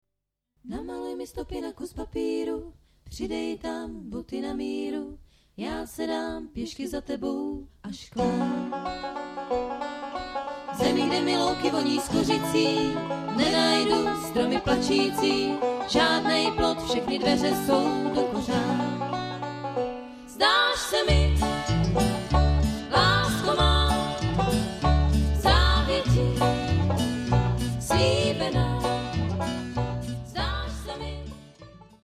jak vypadá dívčí trojhlas a je rozhodnuto.